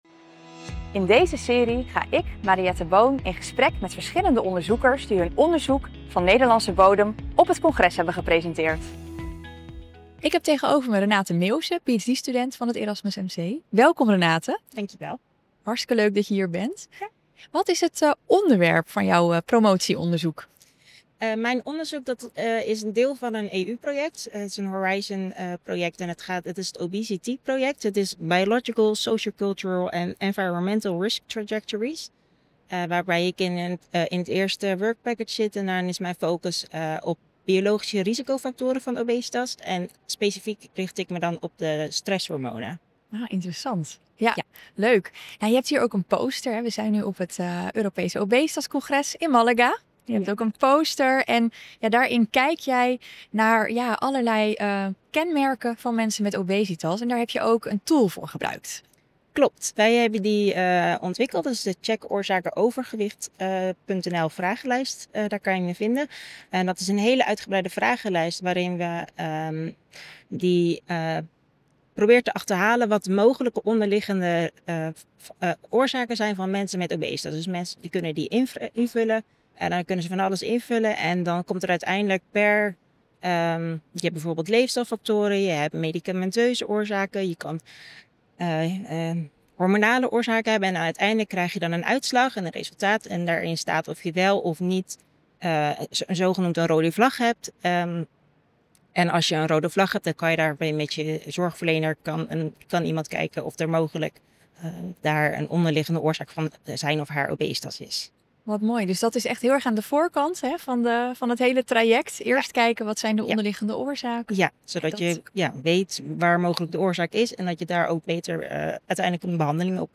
tijdens het European Congress on Obesity 2025 in gesprek met Nederlandse onderzoekers die hun onderzoek presenteerden tijdens het congres